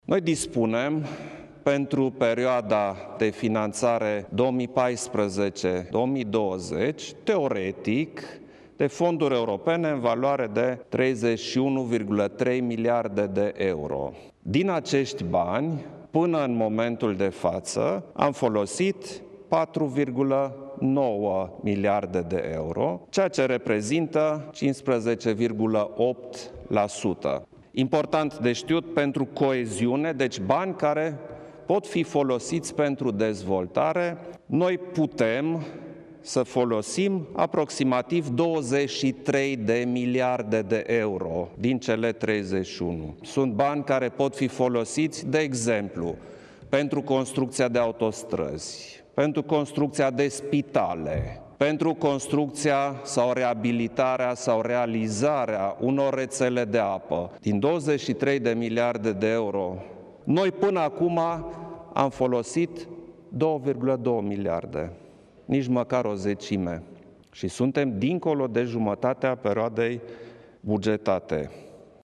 Preşedintele Klaus Iohannis a susținut, în această seară, o declarație de presă, prin care a atenţionat Guvernul să se preocupe mai intens de absorbţia fondurilor europene.